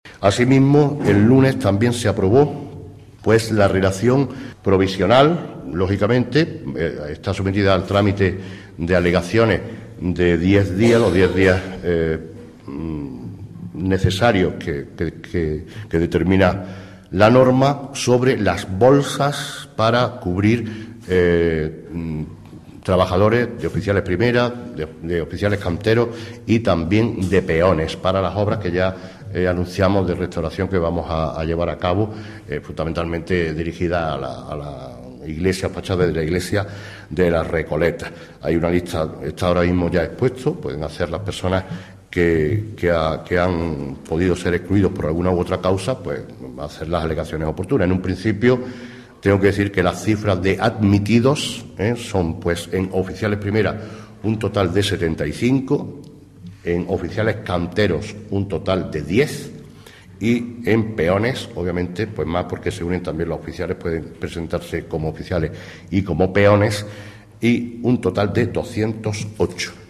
El teniente de alcalde delegado de Obras y Mantenimiento, José Ramón Carmona, ha informado hoy en rueda de prensa la denegación del permiso, por parte de la Consejería de Medio Ambiente y Ordenación del Territorio de la Junta de Andalucía, para poder mejorar la habitual vía de acceso al popular paraje natural de titularidad municipal conocido como el Nacimiento de la Villa.
Cortes de voz
J.R. Carmona   1310.46 kb  Formato:  mp3